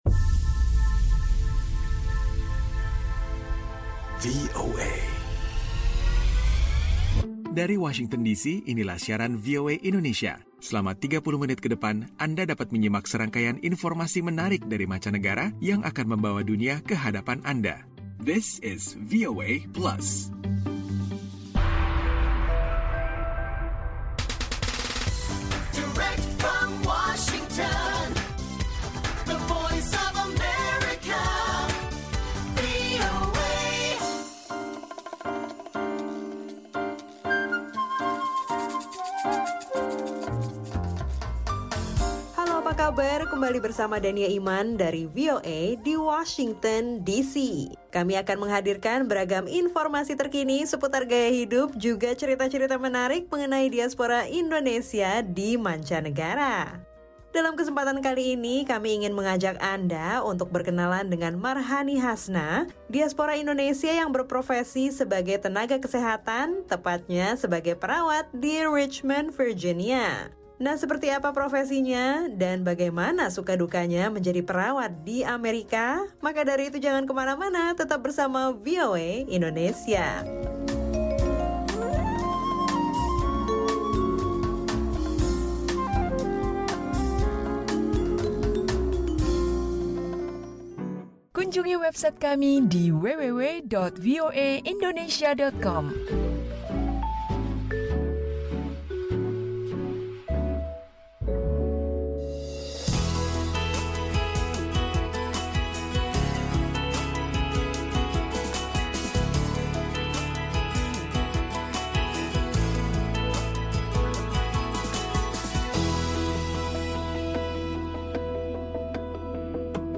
VOA Plus kali ini menghadirkan obrolan bersama seorang diaspora Indonesia seputar profesinya dalam dunia kesehatan sebagai seorang perawat di kota Richmond, negara bagian Virginia.